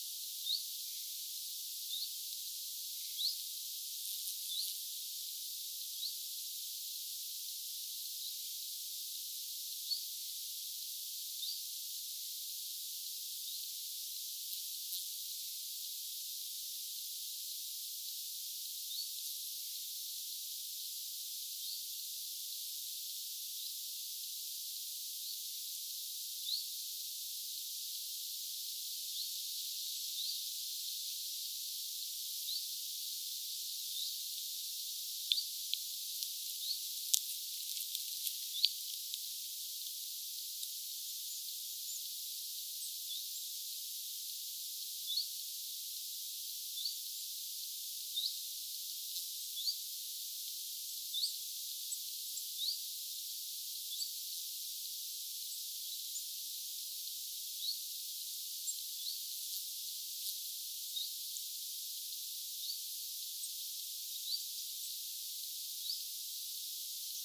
kauempaa kuuluu bizt-tiltaltti
kauempaa_kuuluu_bizt-tiltaltti.mp3